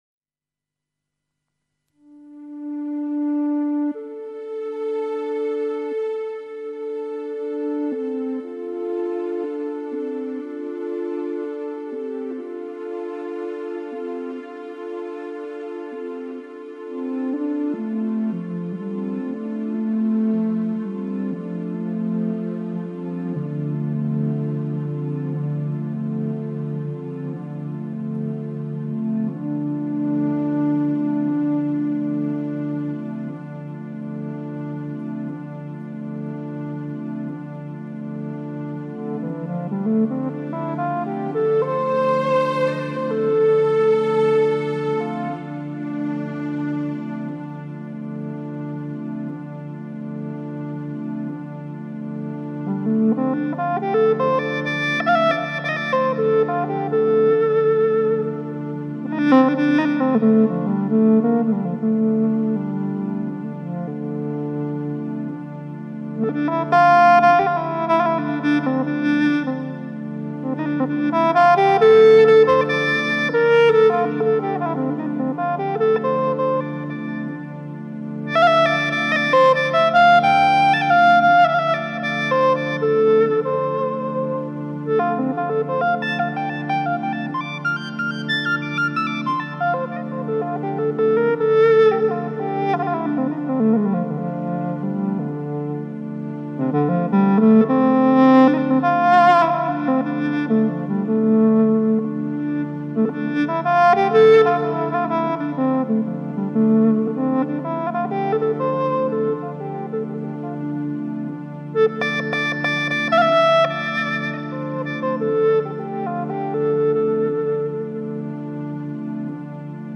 Concerto al Civico Museo del Mare
musica elettronico - acustica minimalista eseguita dal vivo
una musica che fluttua come l'aria, come il mare, che si ripete ma non � mai uguale